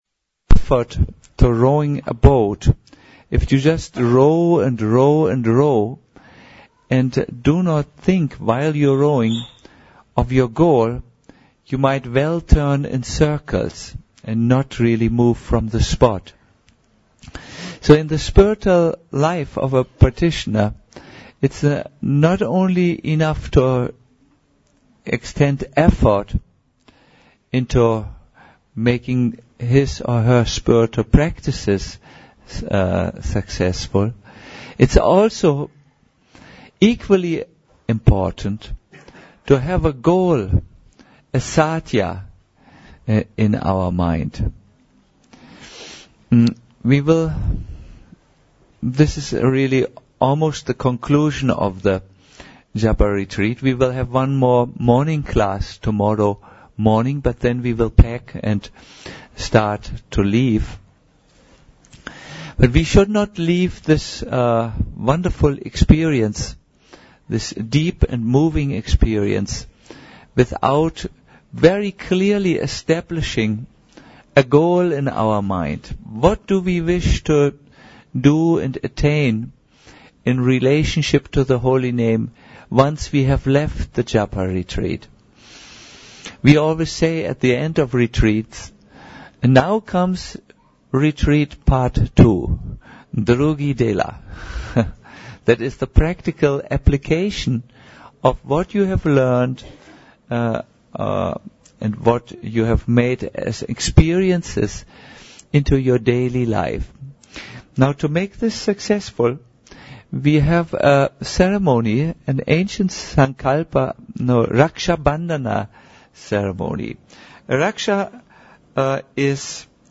Uz blagoslov bhakta koji vode Maharajev tape-ministry evo svih predavanja (na engleskom bez prijevoda) i nekoliko bhajana sa retreat-a...